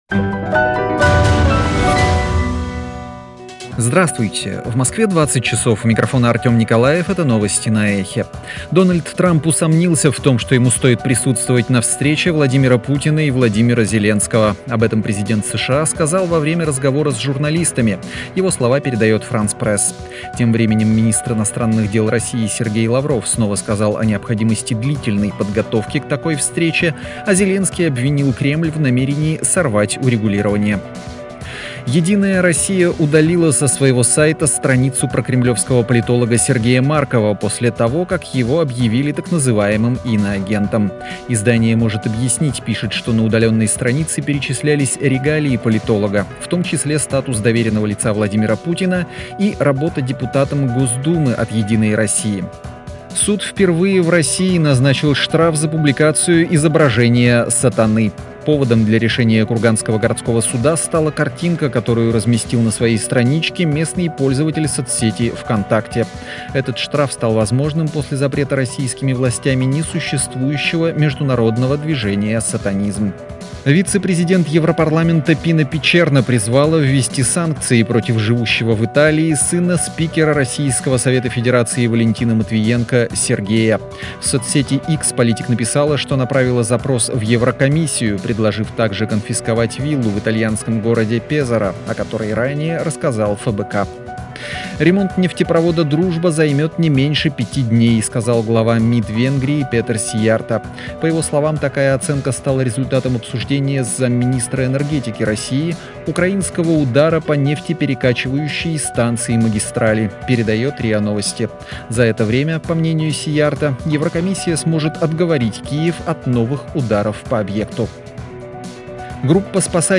Слушайте свежий выпуск новостей «Эха»